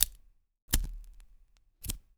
LIGHTER 3 -S.WAV